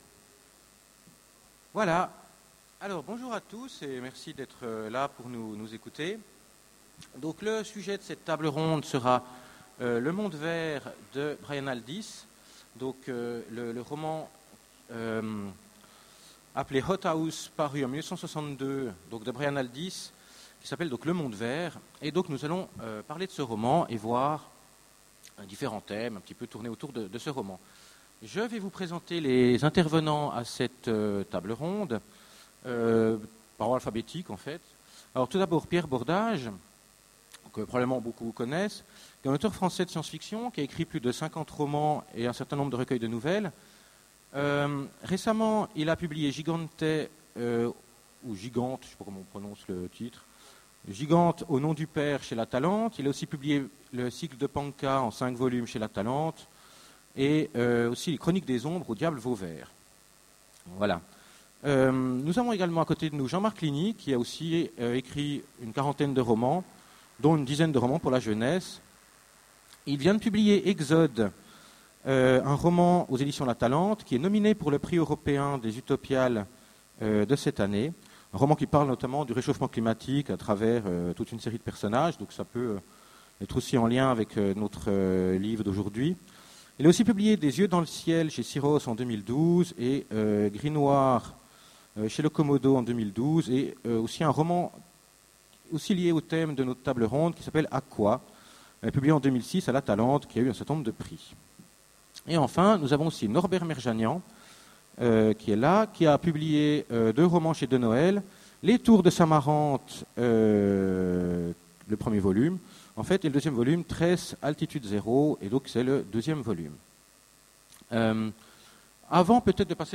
Utopiales 13 : Conférence Le monde vert de Brian Aldiss, un chef d'œuvre ?